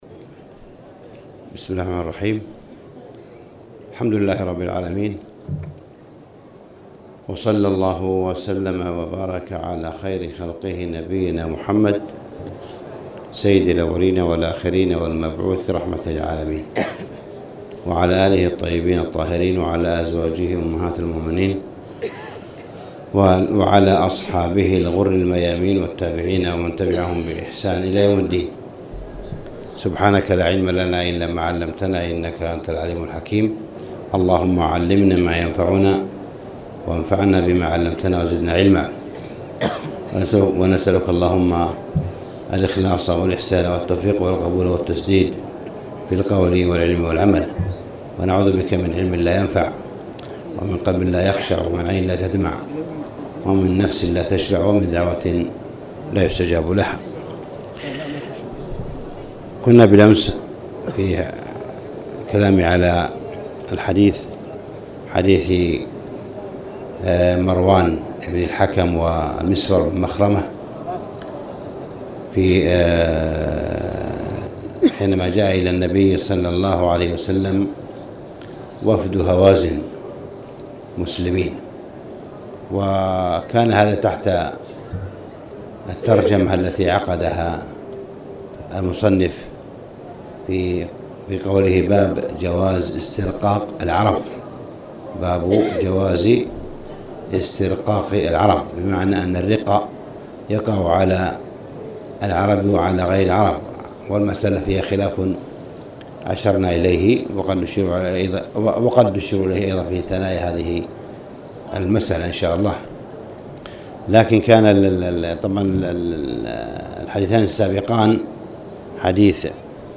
تاريخ النشر ٢ جمادى الأولى ١٤٣٨ هـ المكان: المسجد الحرام الشيخ: معالي الشيخ أ.د. صالح بن عبدالله بن حميد معالي الشيخ أ.د. صالح بن عبدالله بن حميد باب جواز استرقاق العرب